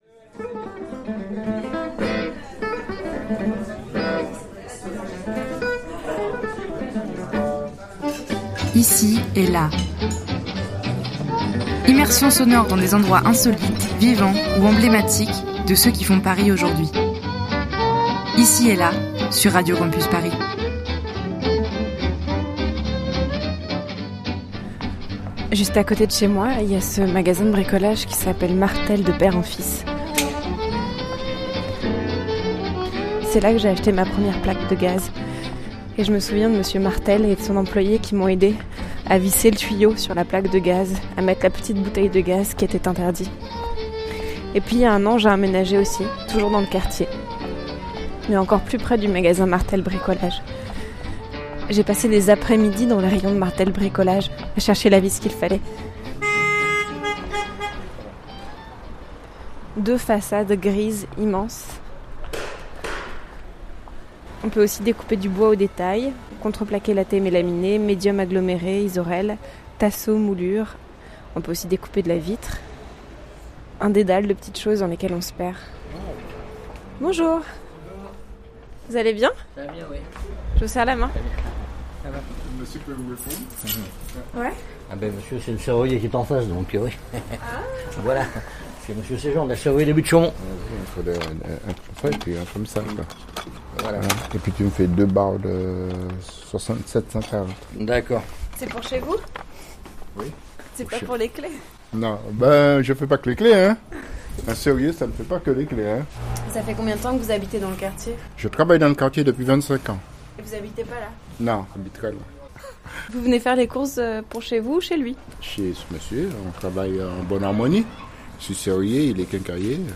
Ce jour-là, en pleines vacances de Pâques, le magasin est calme, si calme que l'on entend les néons grésiller doucement.